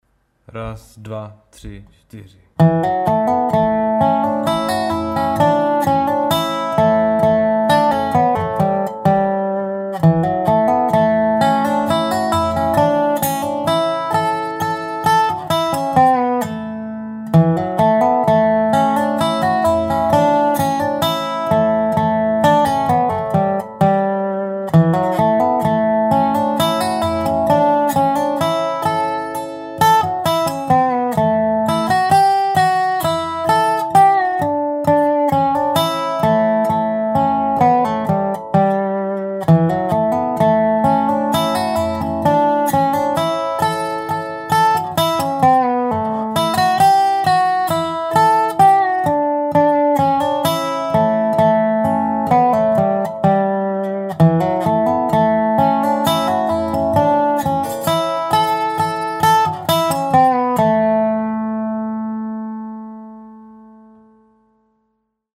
in G major